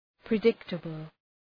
{prı’dıktəbəl}